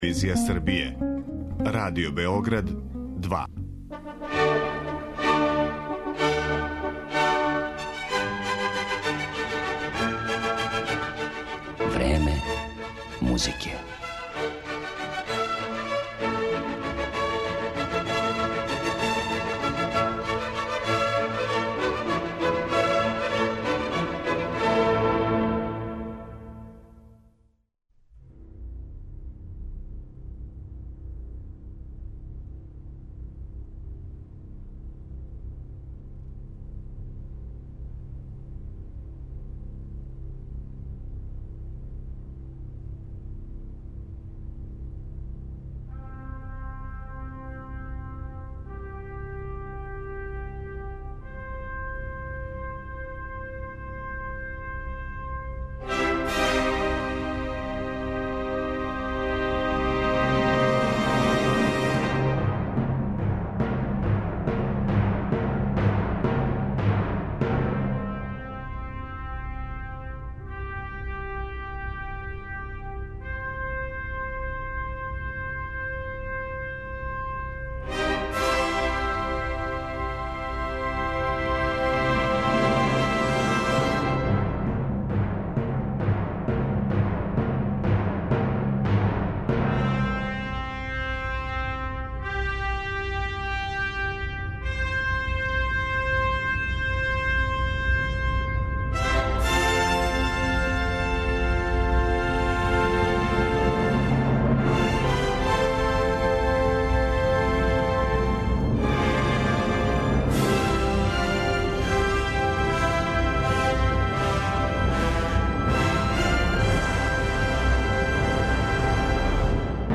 Његов опус чини низ оркестарских дела, као и значајна оперска остварења, из којих ћете чути фрагменте у данашњој емисији